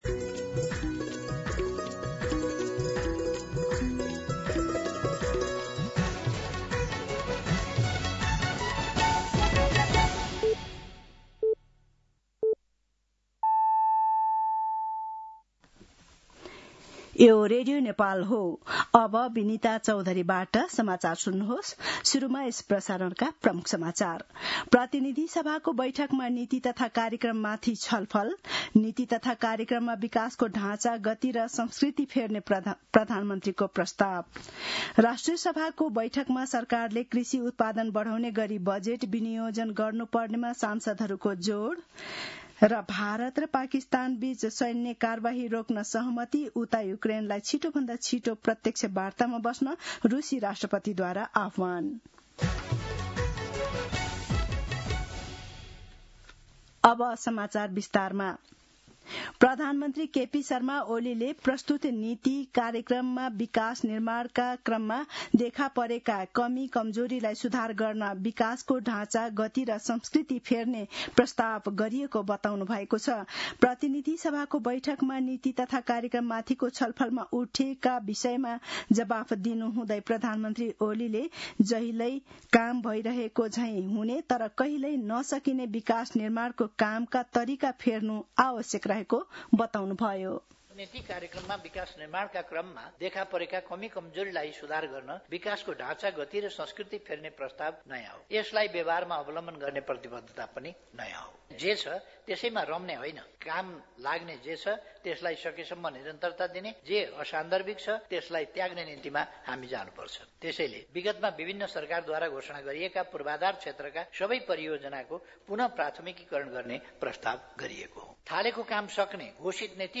दिउँसो ३ बजेको नेपाली समाचार : २८ वैशाख , २०८२
3-pm-Nepali-News-2.mp3